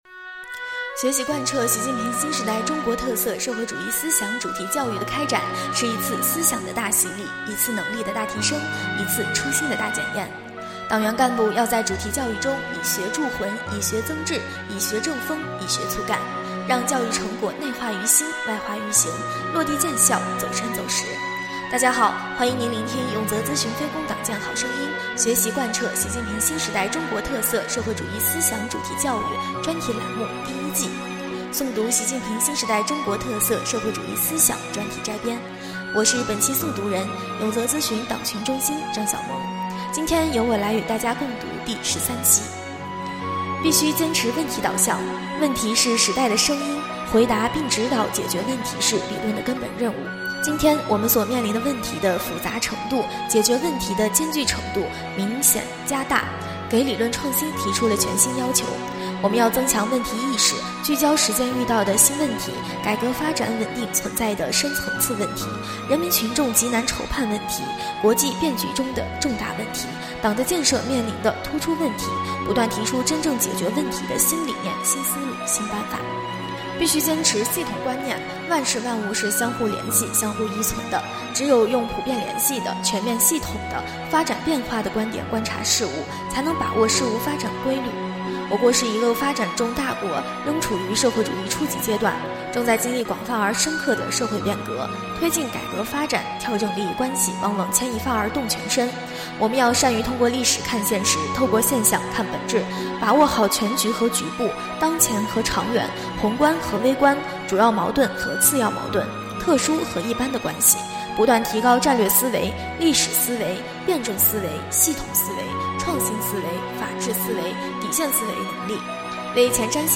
【诵读】《习近平新时代中国特色社会主义思想专题摘编》第13期-永泽党建